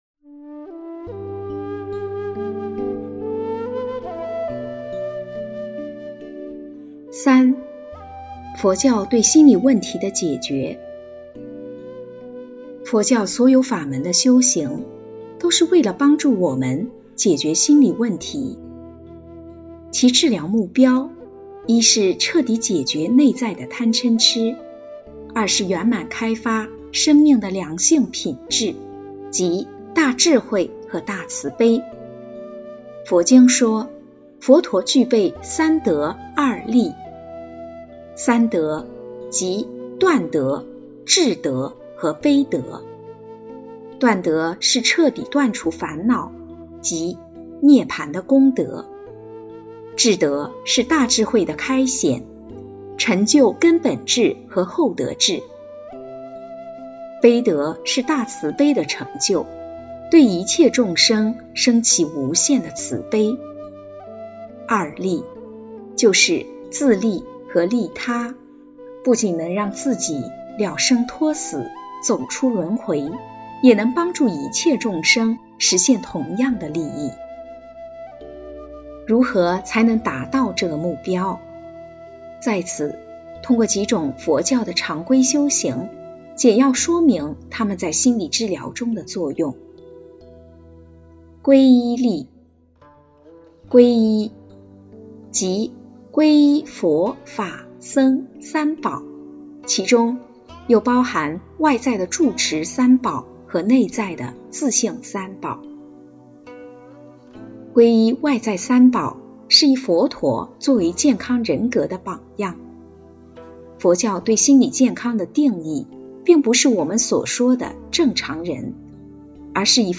心理学视角的佛学世界·3讲 有声书